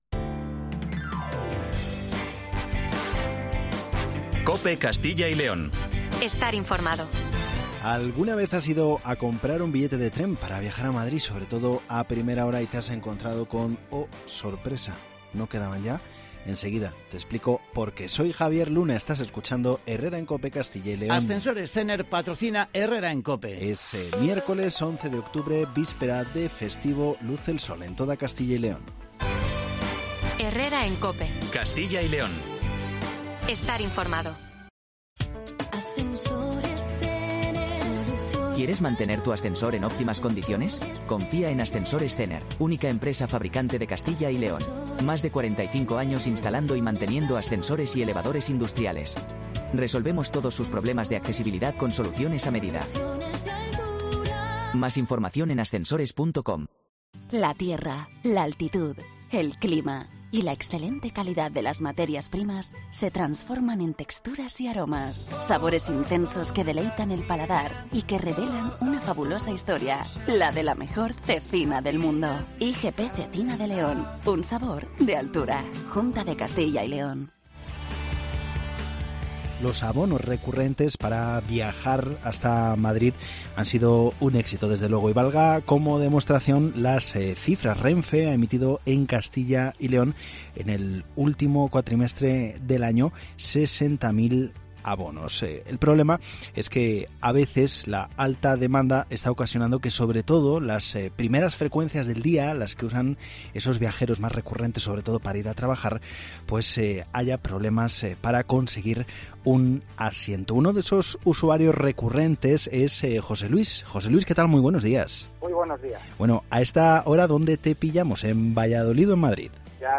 Los abonos recurrentes para viajar a Madrid desde nuestra comunidad han sido todo un éxito pero también han surgido problemas por ese elevado interés. Hablamos con varios usuarios.